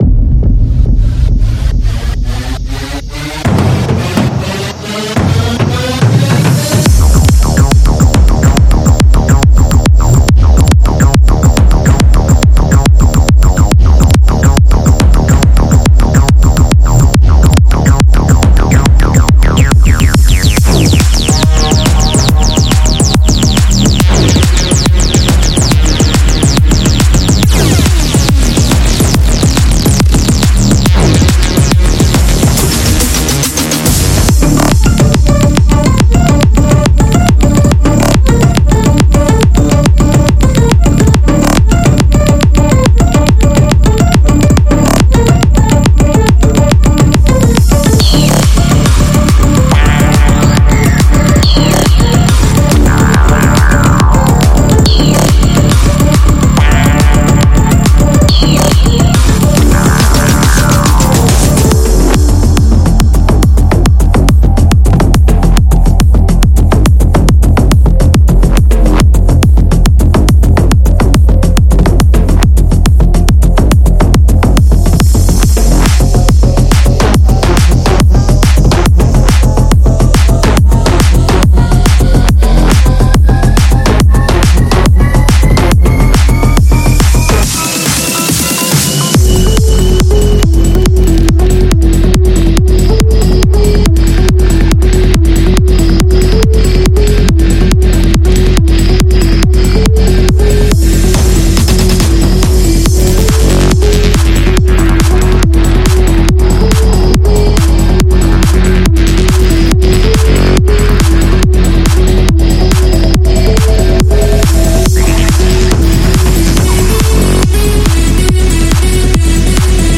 所有音乐元素的末尾都有额外的小节，以捕捉混响/释放/延迟尾巴。